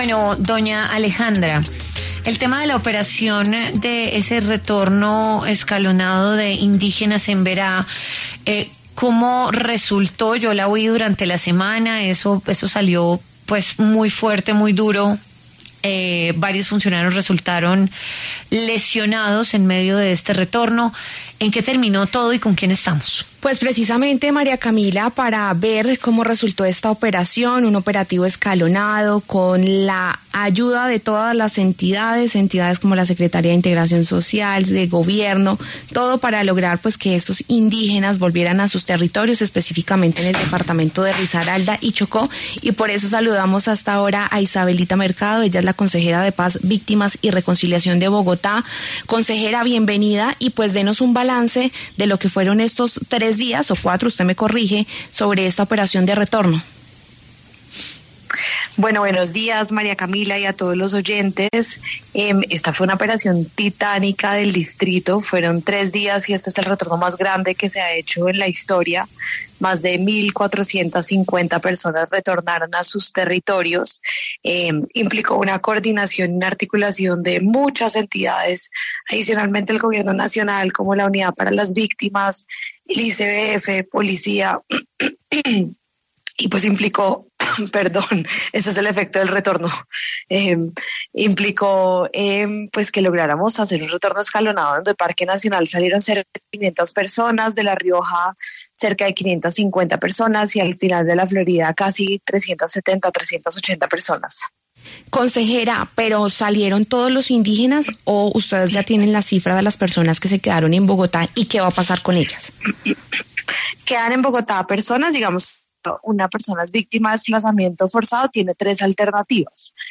En esa línea, habló en los micrófonos de W Fin De Semana Isabelita Mercado, consejera de Paz, Víctimas y Reconciliación, para referirse a la coyuntura.